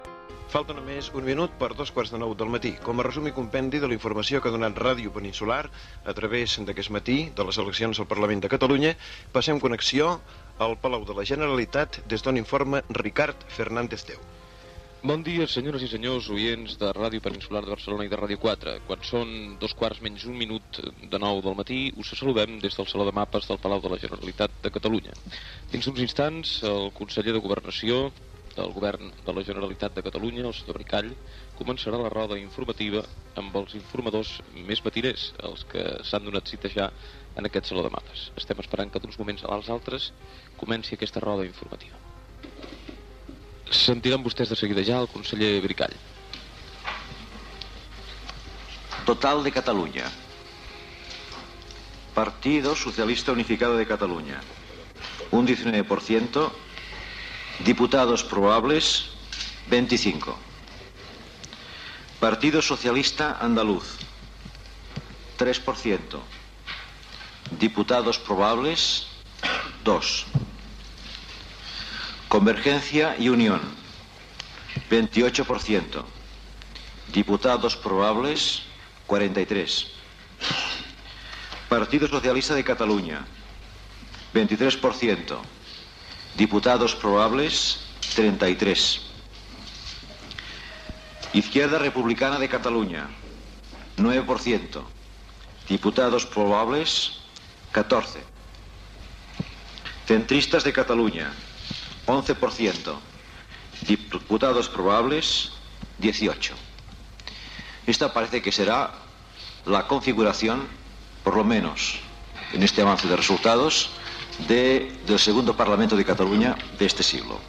Hora i connexió amb el Palau de la Generalitat on el conseller Josep Maria Bricall dóna el resultat de les eleccions al Parlament de Catalunya celebrades el dia anterior
Informatiu